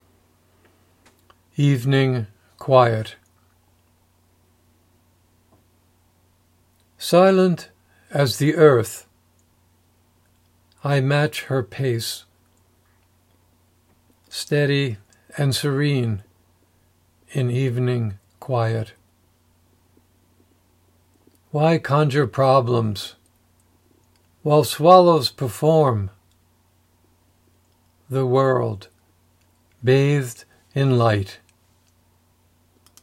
Reading of “Evening Quiet”